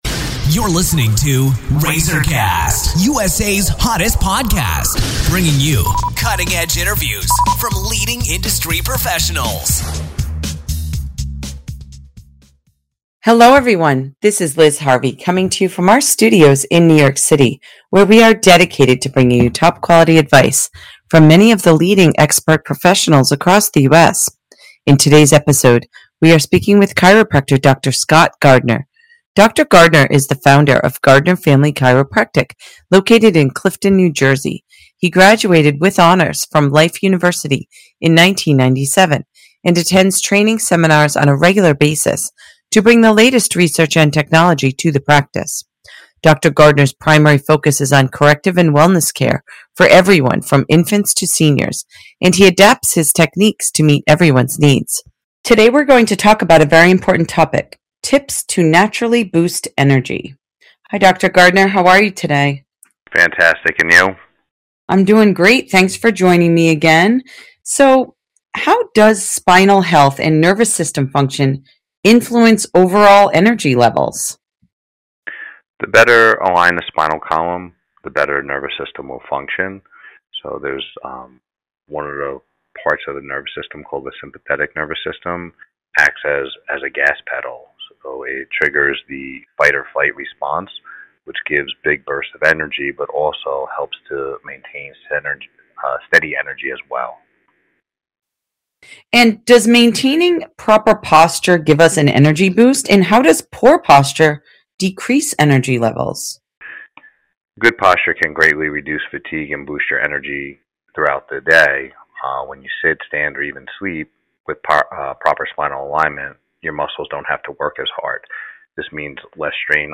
Informative health interviews designed to empower you with the tools to live the happiest and healthiest life imaginable!